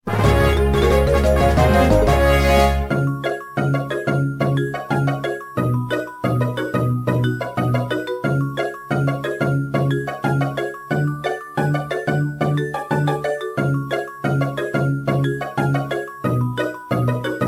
Ripped from the remake's files
trimmed to 29.5 seconds and faded out the last two seconds